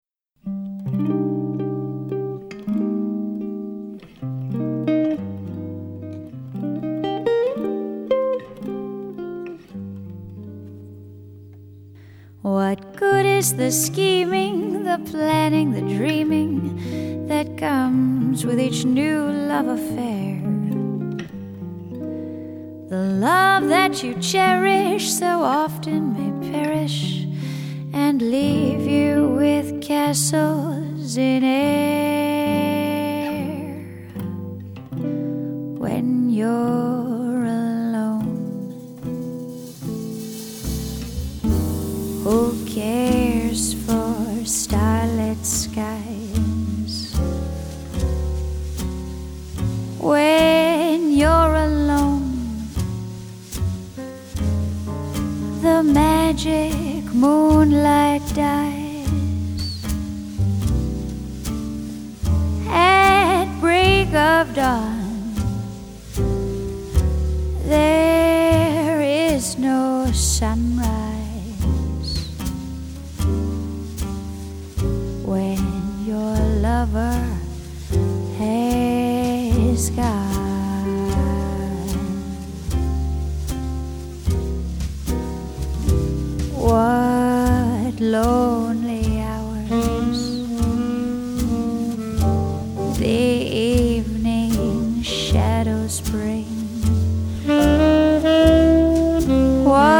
vocals
tenor saxophone, clarinet, flute
guitar
piano
bass
drums